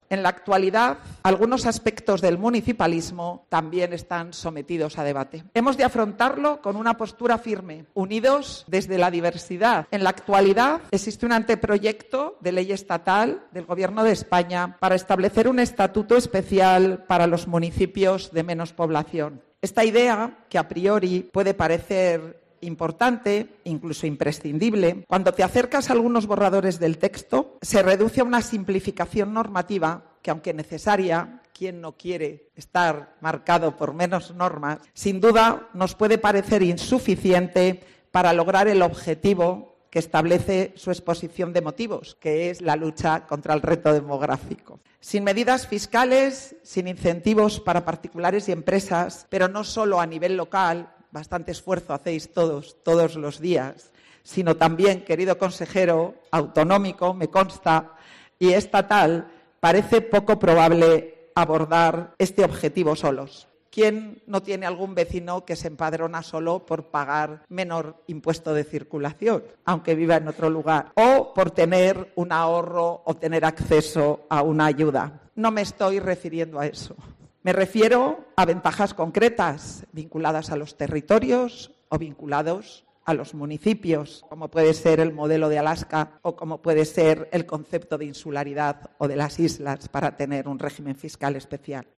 En Cervera de Pisuerga, durante la celebración del Día de la Provincia y ante los alcaldes del los 191 municipios de Palencia, Armisén propuso la implementación de medidas fiscales y de incentivos similares a los "modelos de Alaska o insularidad con régimen fiscal especial", pero específicamente diseñados para zonas rurales.